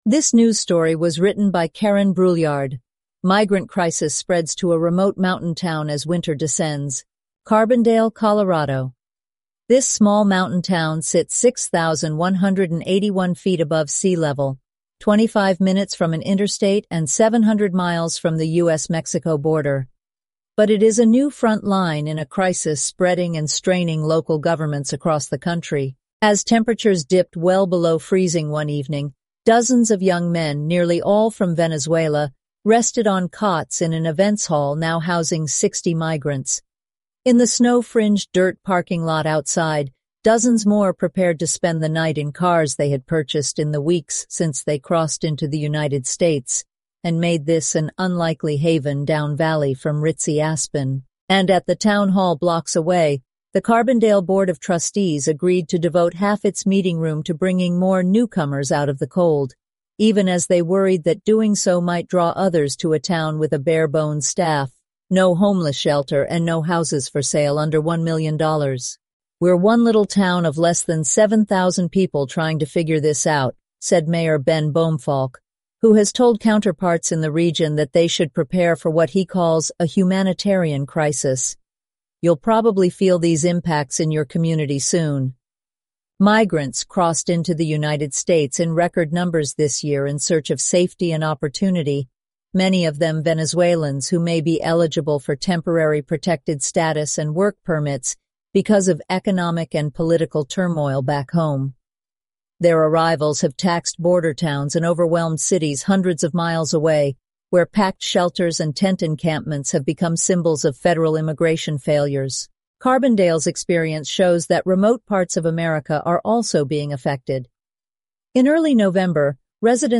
eleven-labs_en-US_Maya_standard_audio.mp3